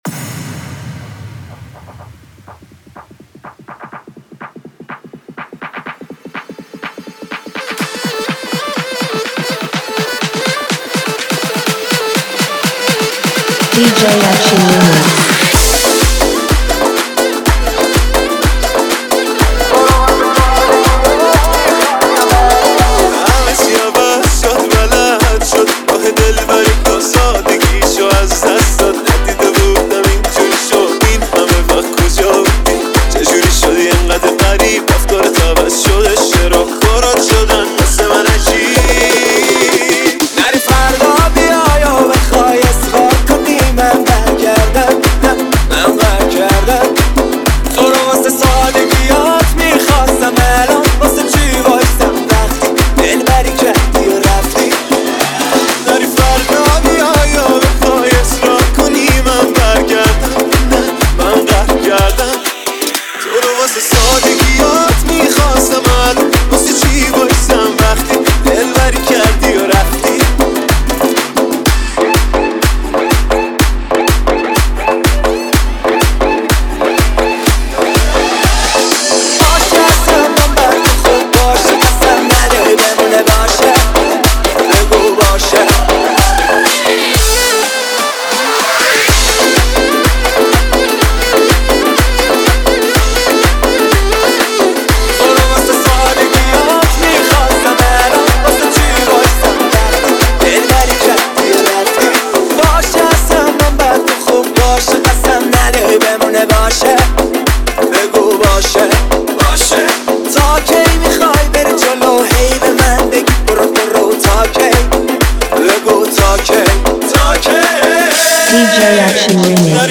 ریمیکس
ریمیکس تند بیس دار